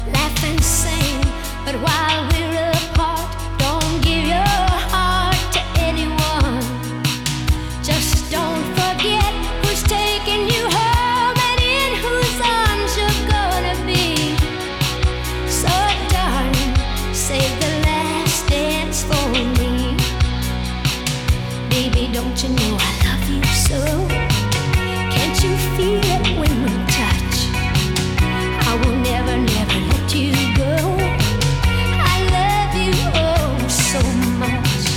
Жанр: Кантри
Country, Traditional Country, Honky Tonk